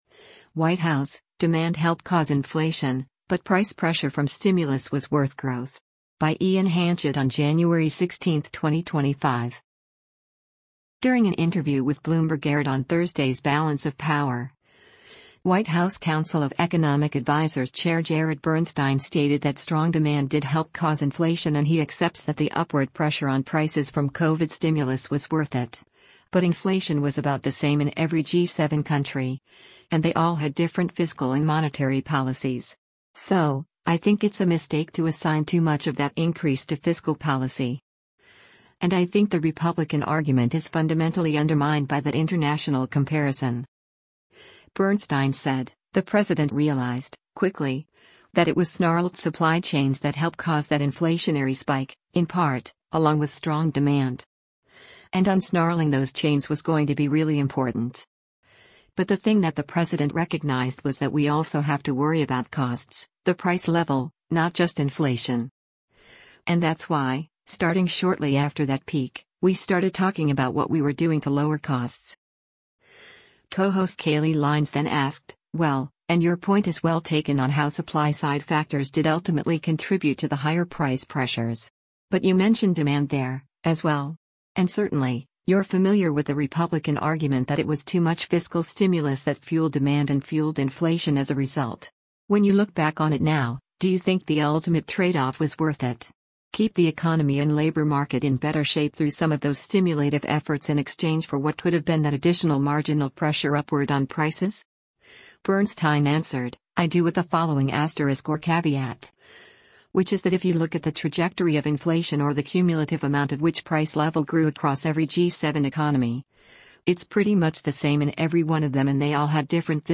During an interview with Bloomberg aired on Thursday’s “Balance of Power,” White House Council of Economic Advisers Chair Jared Bernstein stated that “strong demand” did help cause inflation and he accepts that the upward pressure on prices from COVID stimulus was worth it, but inflation was about the same in every G7 country, “and they all had different fiscal and monetary policies.